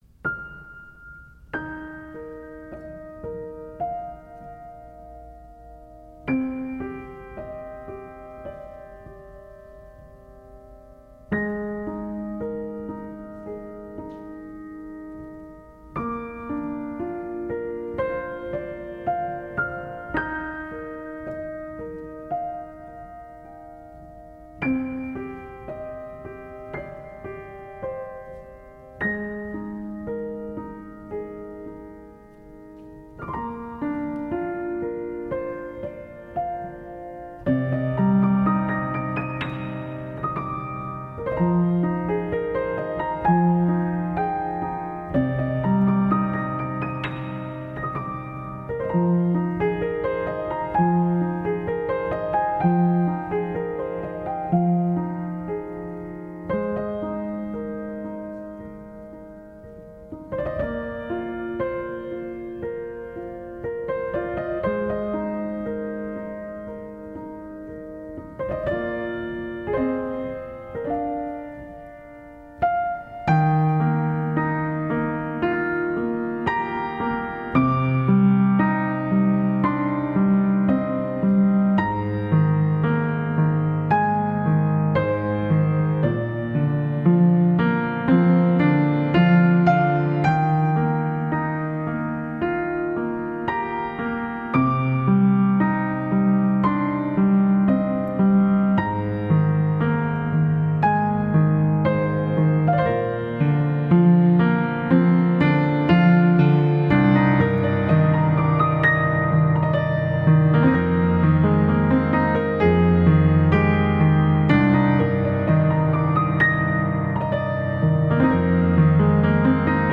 音乐类型：New Age / Piano